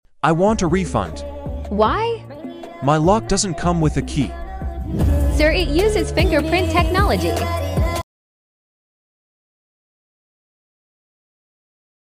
Fingerprint Lock Home Luggage -